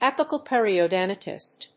apical+periodontitis.mp3